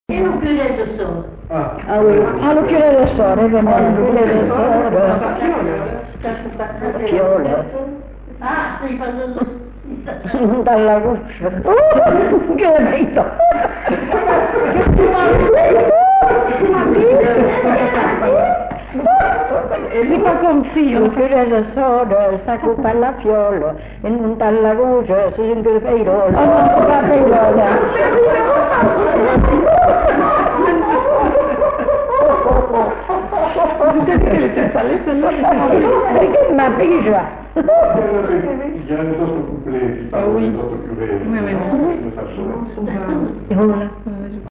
Aire culturelle : Bazadais
Lieu : Uzeste
Genre : chant
Effectif : 1
Type de voix : voix de femme
Production du son : chanté